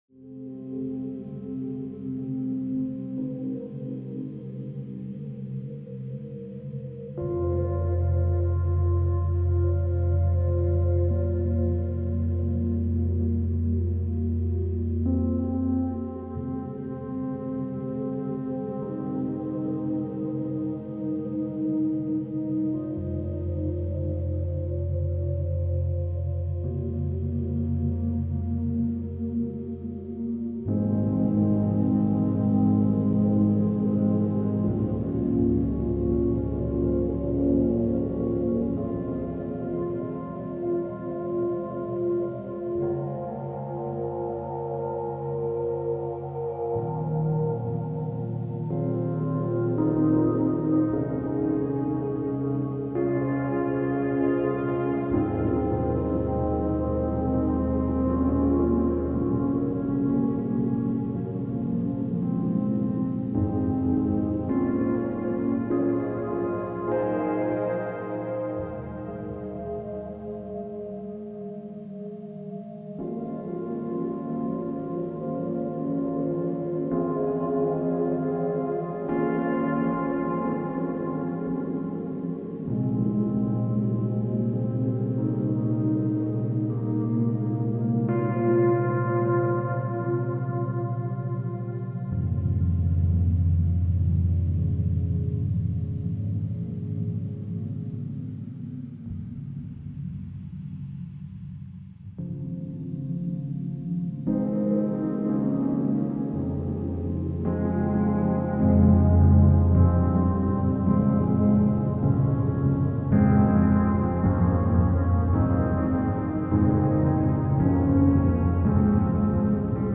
スローテンポ暗い穏やか